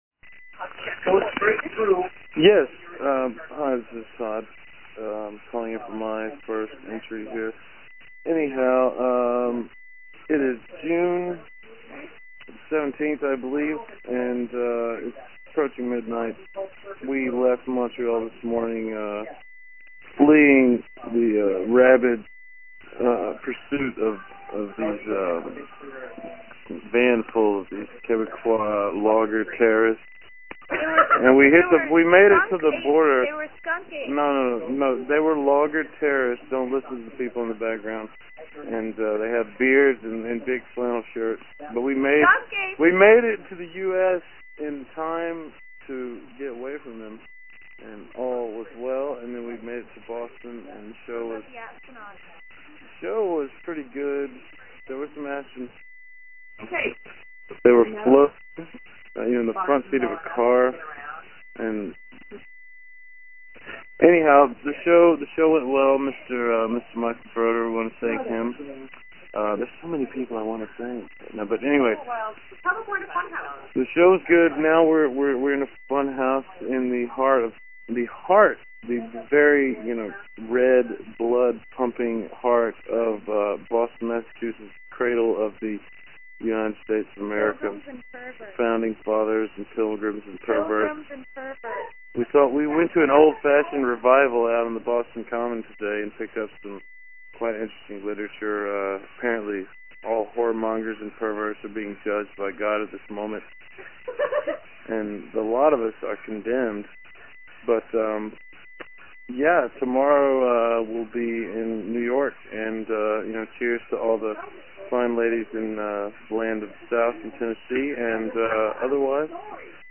Every day they were on the road, one of them called and left a message about something that happened that day, and they were posted below.